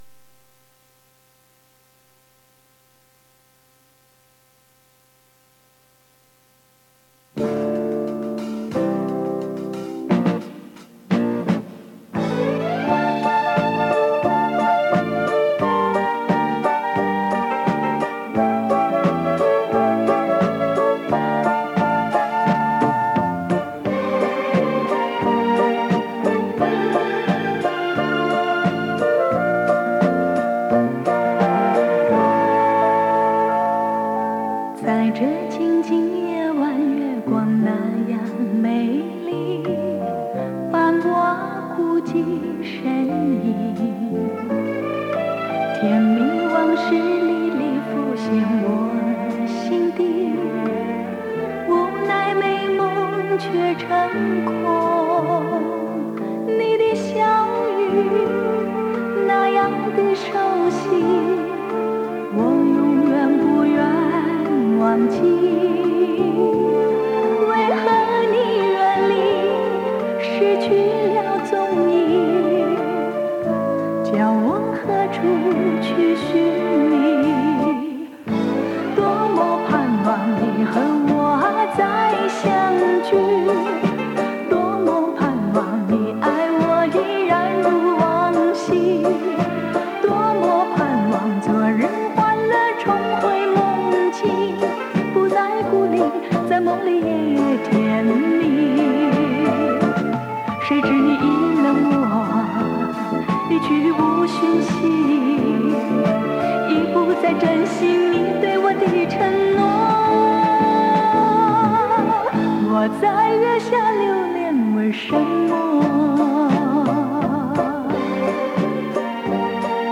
磁带数字化：2022-08-12
改编自日本作品和国人作品各占部分，以慢歌抒情为主兼有快歌。